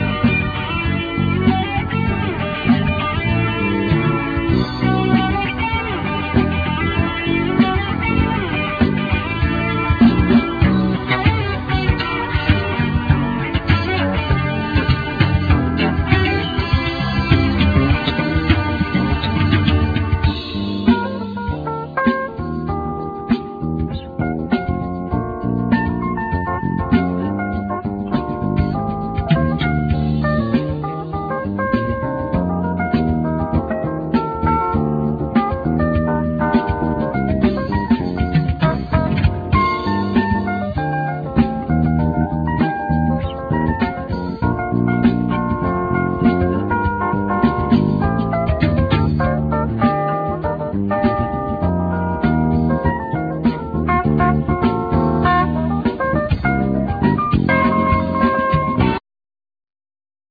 Alto sax,Synthesizer
Keyboards
Guitar
Bass
Tenor sax
Drums
Percussions